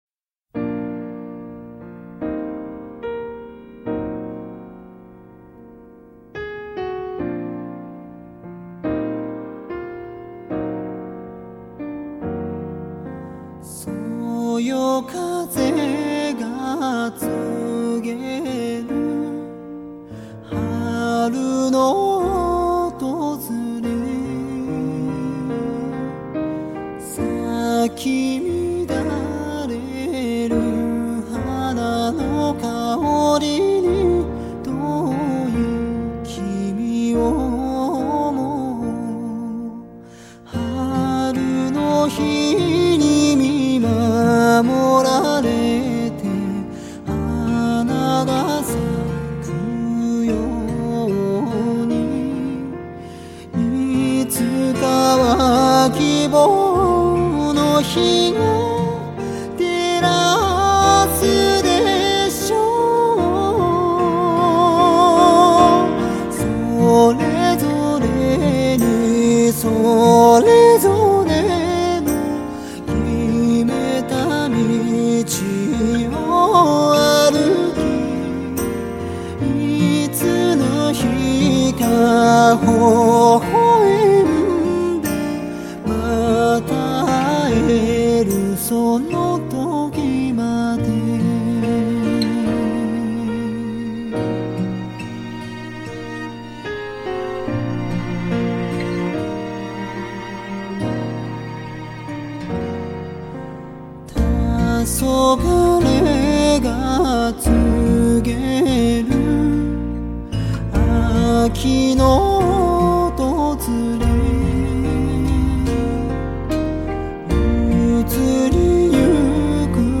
日文主题曲